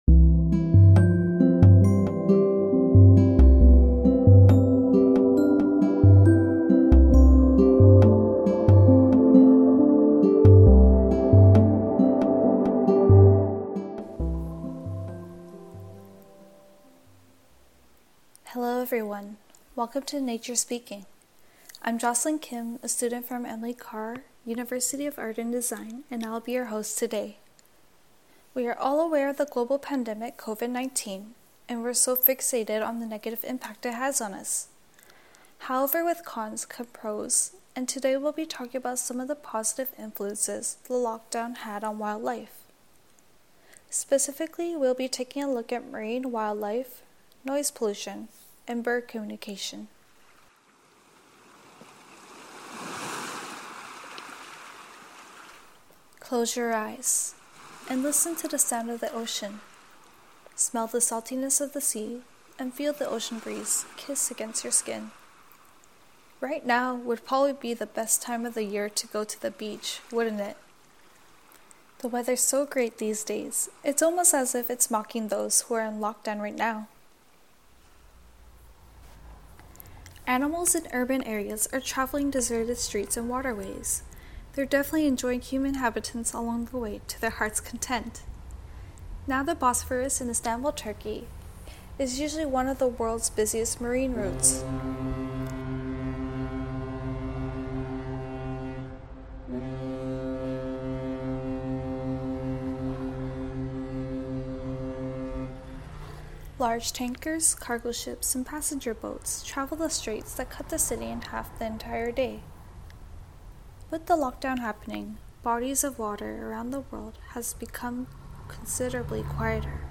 Audio non-musical